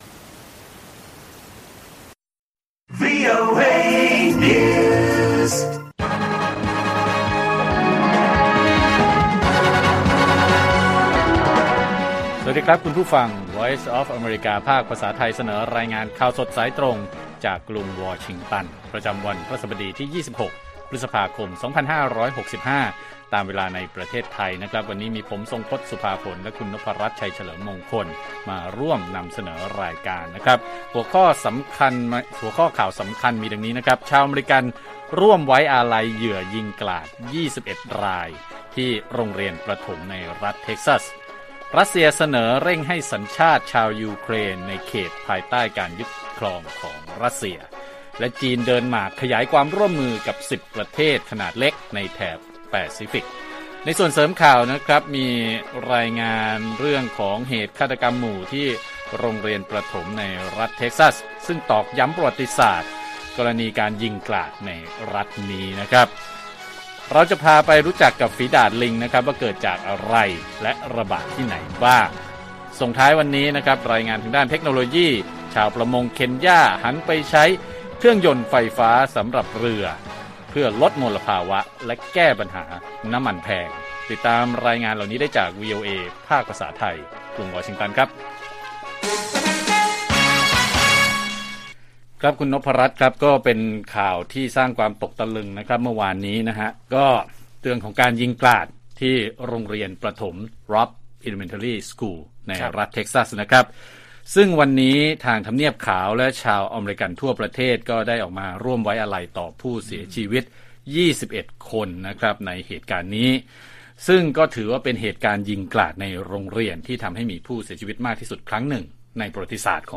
ข่าวสดสายตรงจากวีโอเอไทย พฤหัสฯ 26 พ.ค. 65